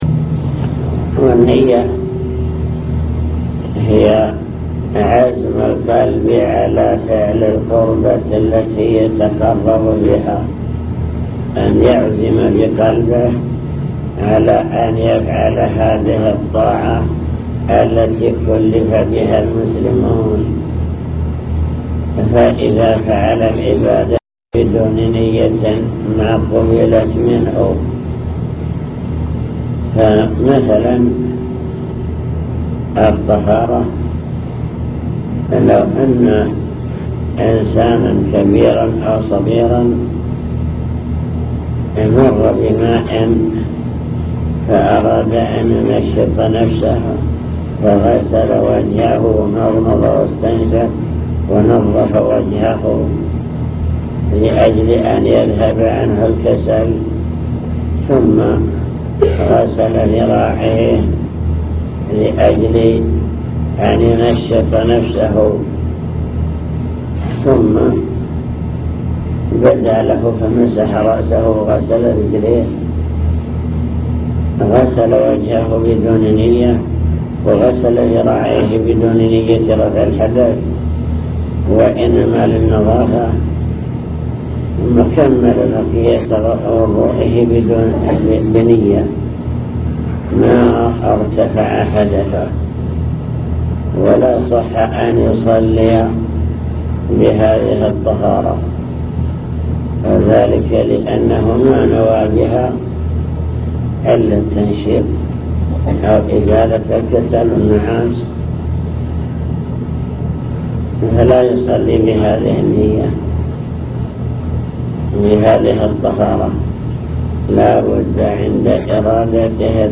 المكتبة الصوتية  تسجيلات - محاضرات ودروس  درس الفجر